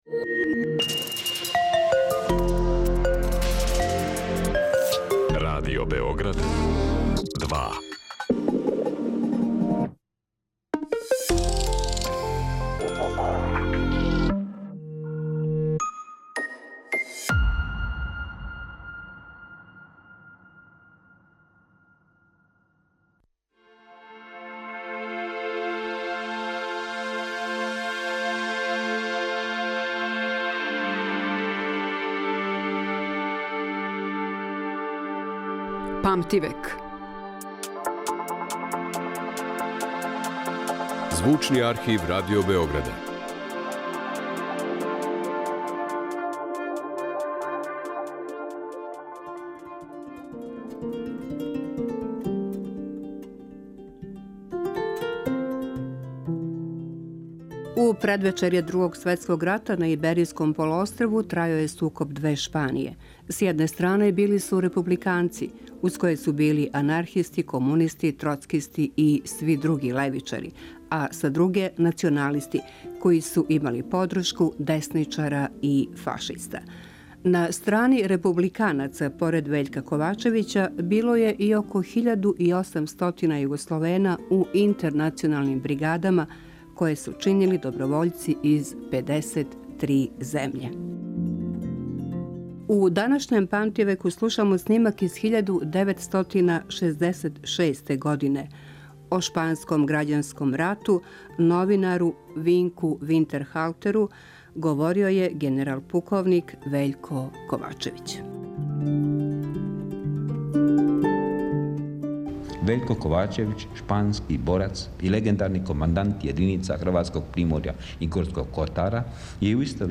О шпанском грађанском рату говори генерал-пуковник Вељко Ковачевић.
У данашњем Памтивеку слушамо снимак из 1966. године о шпанском грађанском рату на коме говори генерал-пуковник Вељко Ковачевић.